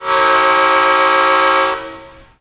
"X P5A horn"